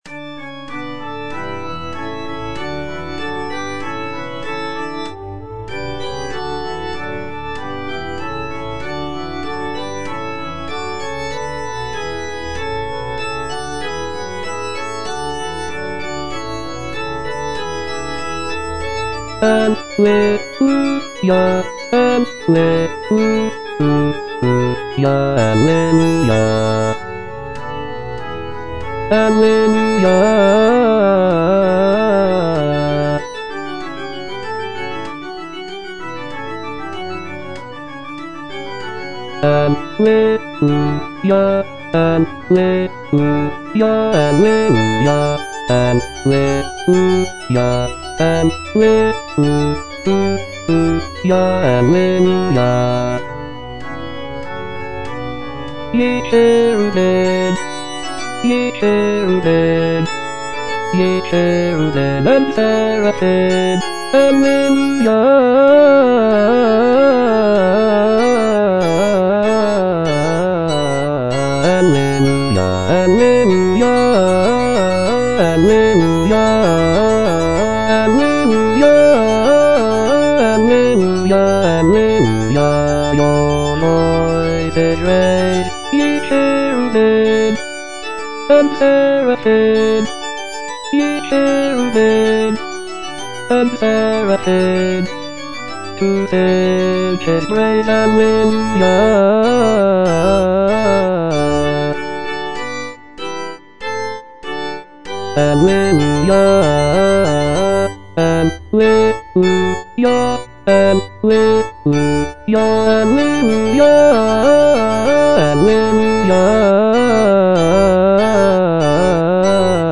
(A = 415 Hz)
Bass (Voice with metronome) Ads stop